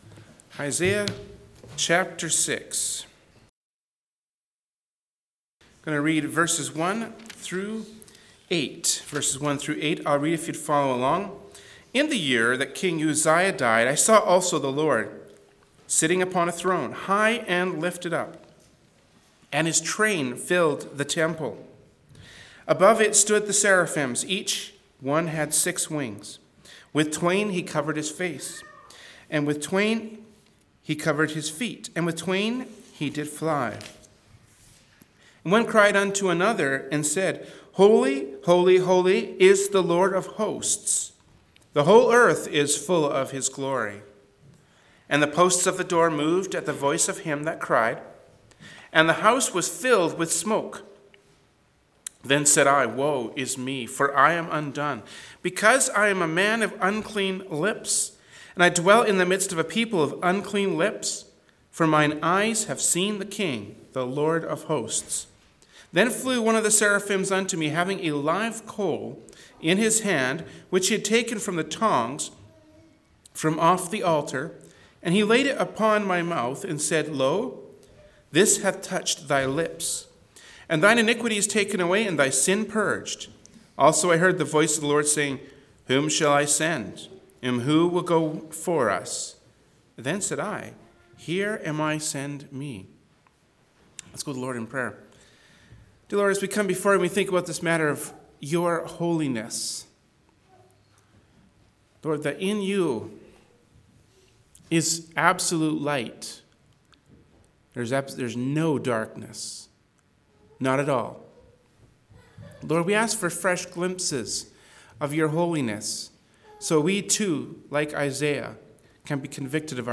Passage: Isaiah 6:1-8 Service Type: Wednesday Evening Service “Isaiah 6:1-8” from Wednesday Evening Service by Berean Baptist Church.
Genre: Preaching. Topics: God's Holiness « Distraction or Opportunity